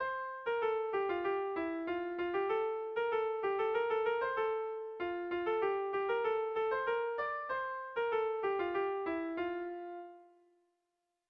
Gabonetakoa
Kopla handia
ABD